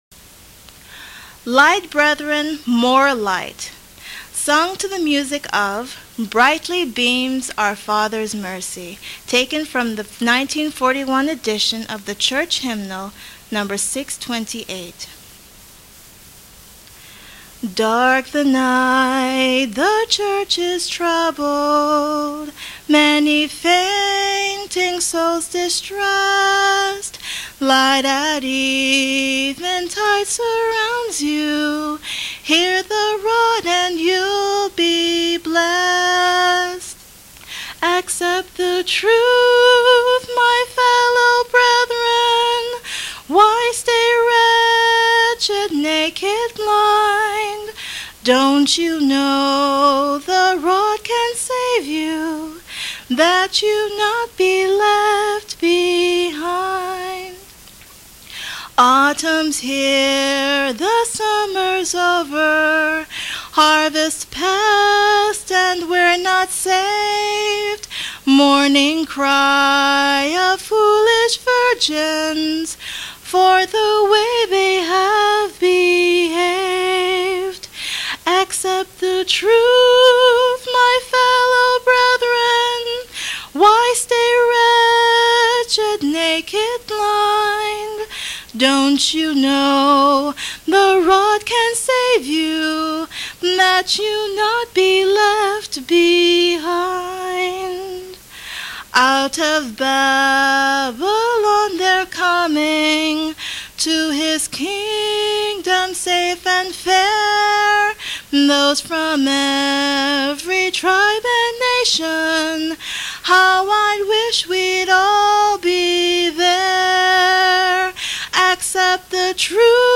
Sung without instrumental accompaniment